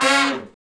Index of /90_sSampleCDs/Roland LCDP06 Brass Sections/BRS_R&R Horns/BRS_R&R Falls